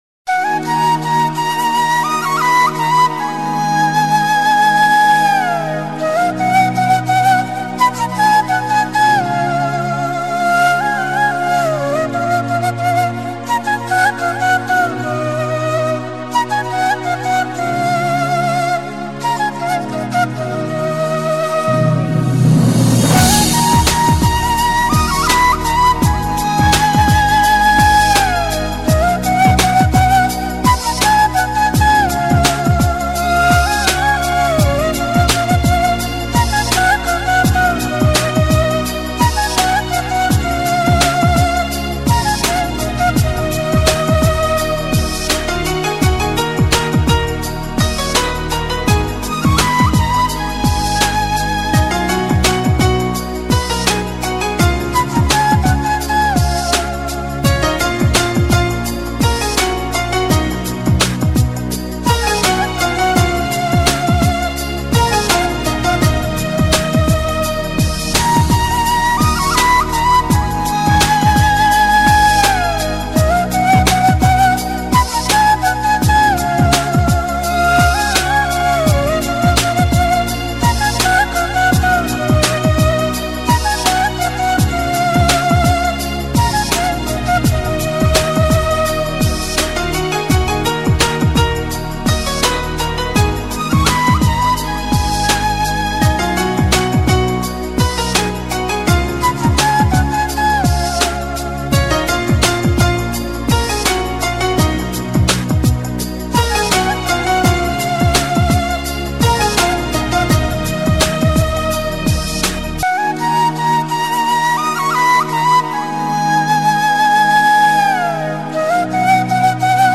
srednevekovaya_vostochnaya_muzyka___fleyta_v_obrabotke_.mp3